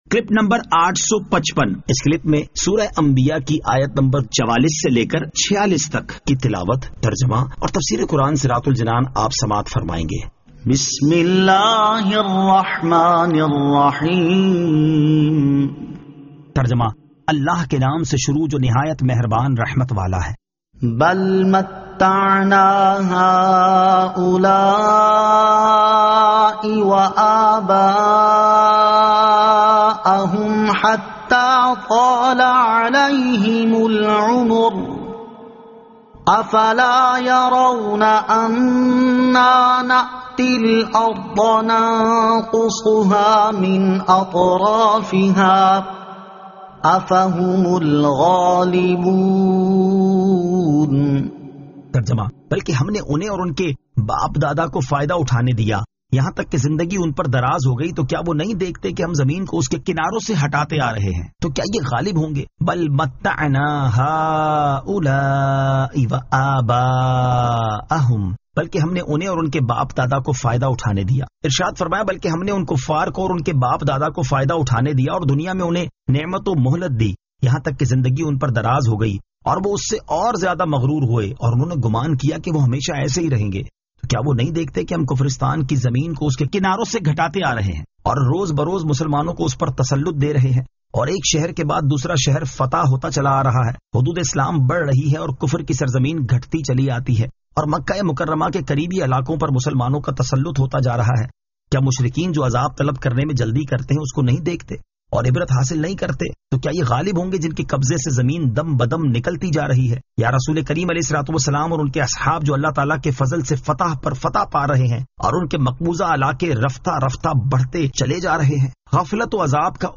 Surah Al-Anbiya 44 To 46 Tilawat , Tarjama , Tafseer